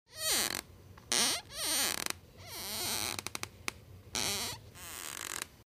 Звуки скрипа дерева
На этой странице собрана коллекция натуральных звуков скрипящего дерева. Вы можете слушать онлайн или скачать в mp3 скрип старых половиц, веток в лесу, дверей и элементов деревянного дома.